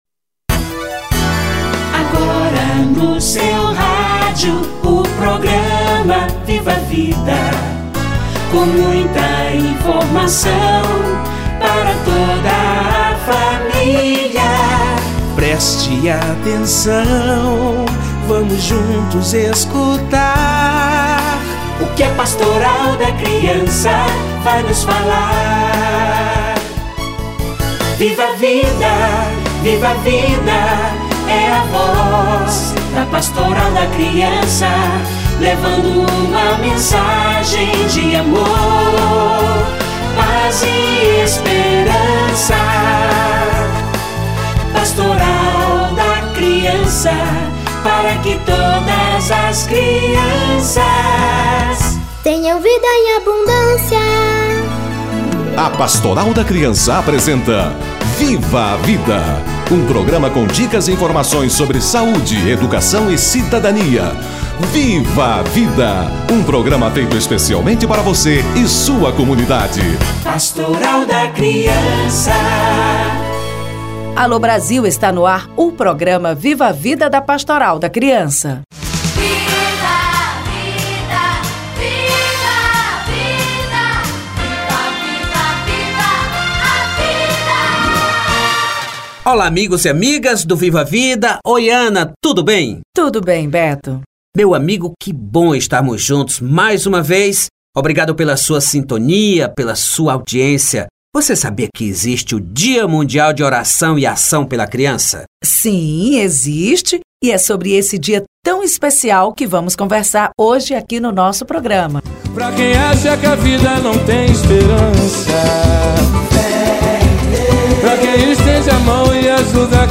Dia de Oração e Ação pela Criança - Entrevista